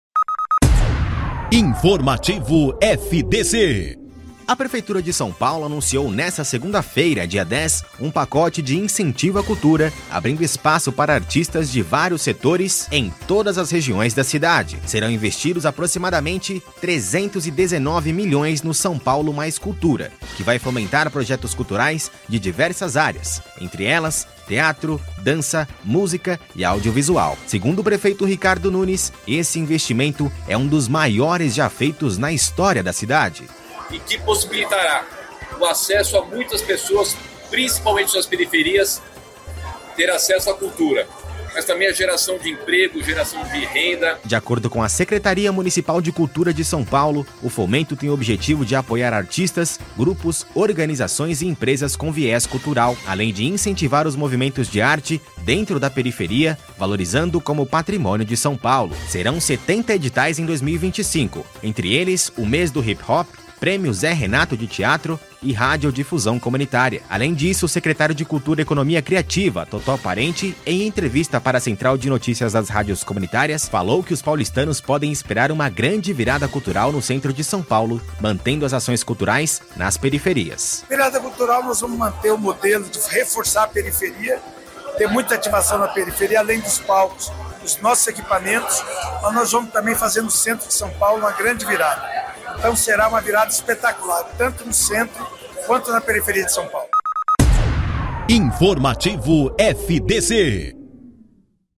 Ouça a notícia: São Paulo anuncia maior investimento cultural da história